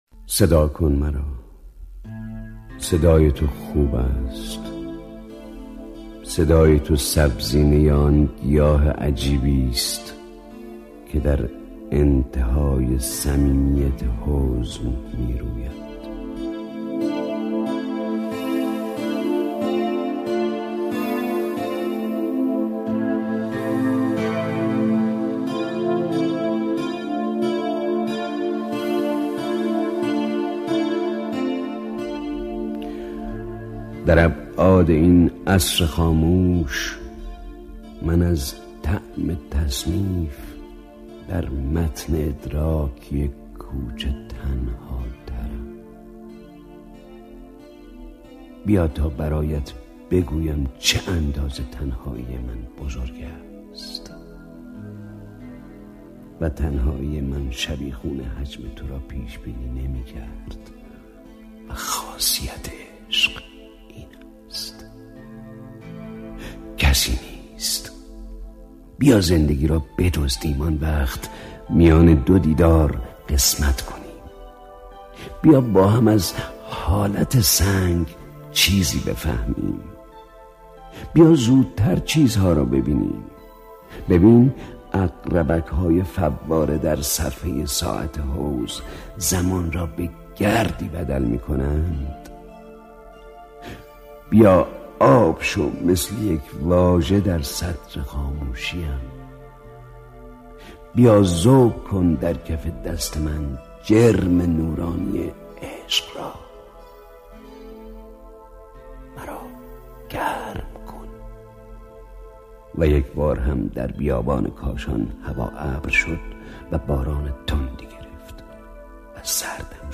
تکیه | دکلمه صدا کن مرا